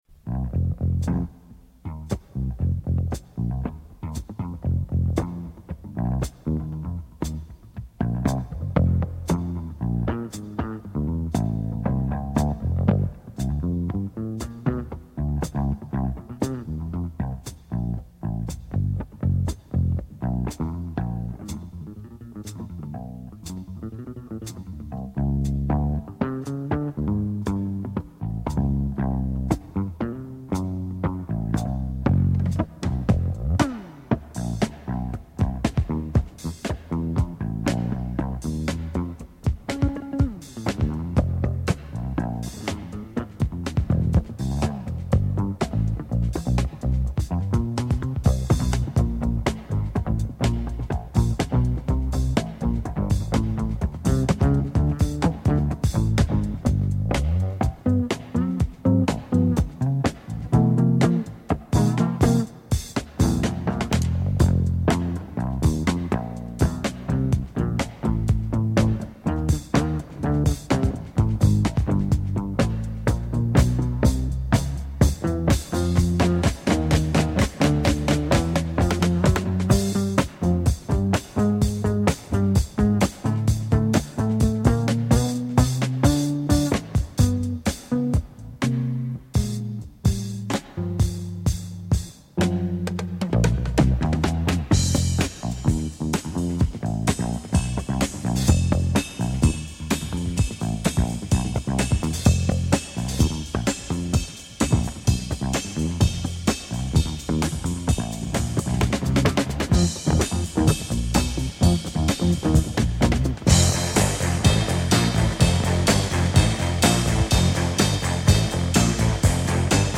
E o duelo cantora-trompete